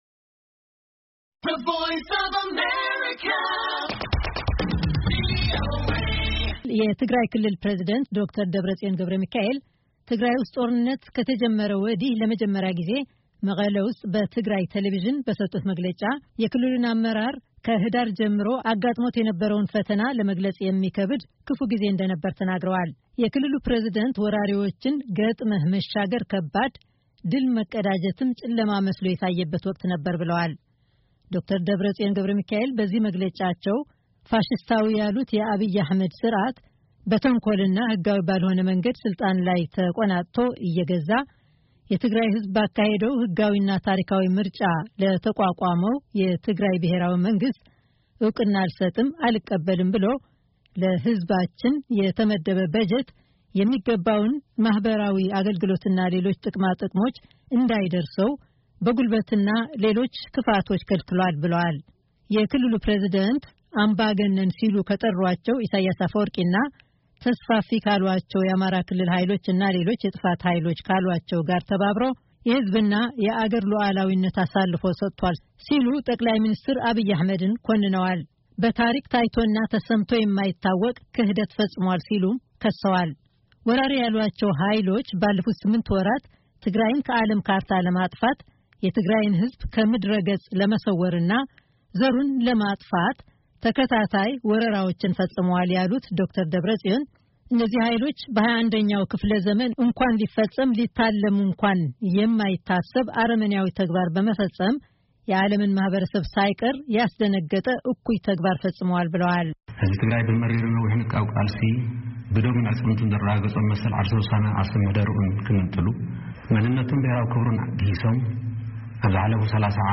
ዶ/ር ደብረጽዮን ገ/ሚካኤል የሰጡት መግለጫ